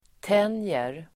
Uttal: [t'en:jer]